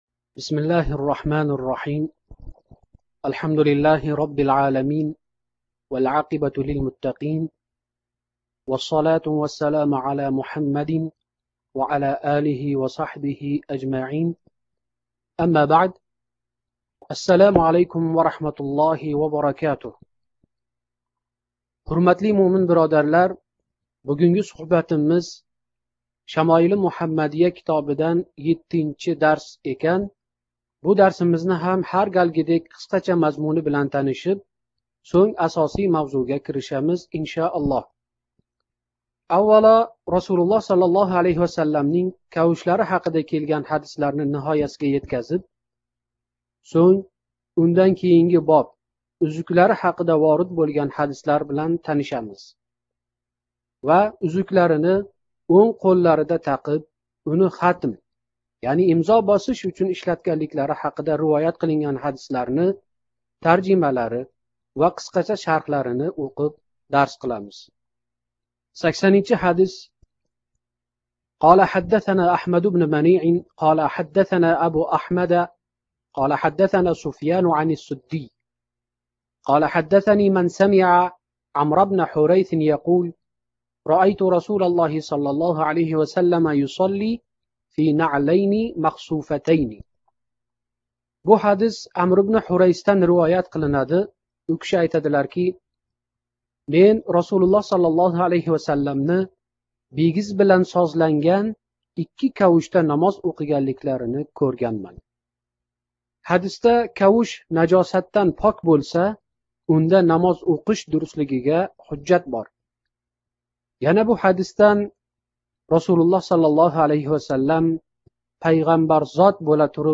“Шамоили Муҳаммадийя” ўзбекча шарҳи 7 – дарс : Talaba